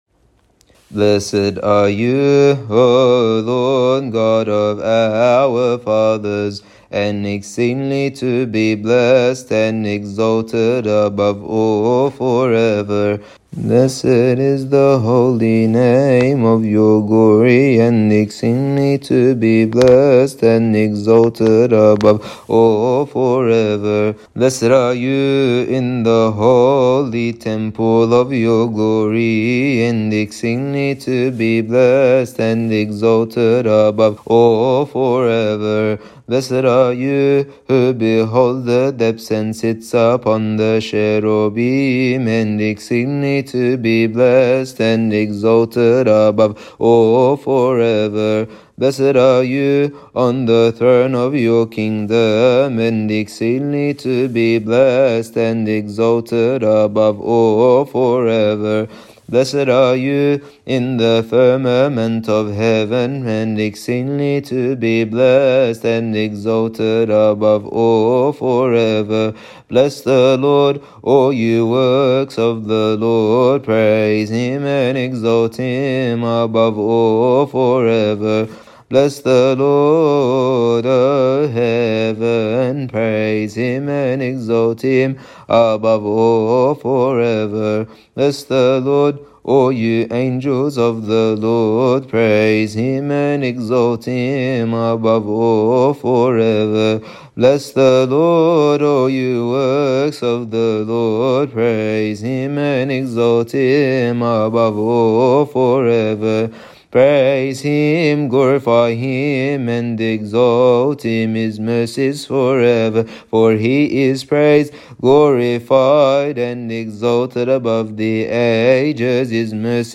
Find here tunes that are used during the Service of the Joyous Saturday
Third_Hoas-Apocalypse-Tune.mp3